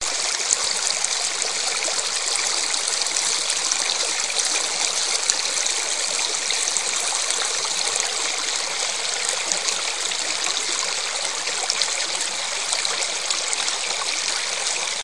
自然 " 哈索特河
描述：山河Khasaut，KarachayCherkessia，俄罗斯
标签： 小溪 河流 溪流 沙沙 沙沙
声道立体声